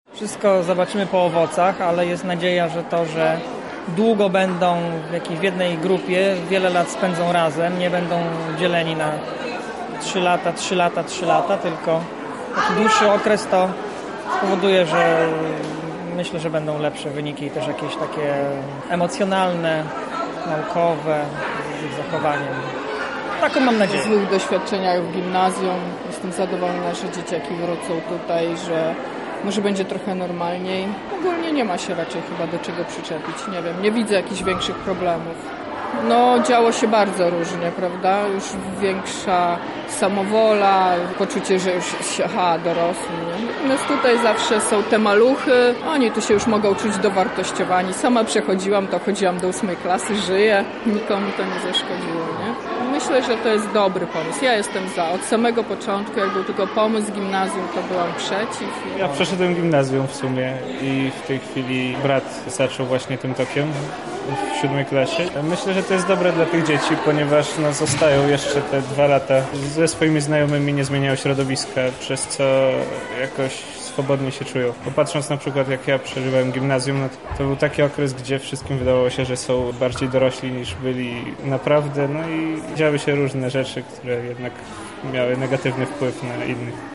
Nasz reporter spytał rodziców o to, jak oceniają zmiany, które zachodzą w edukacji.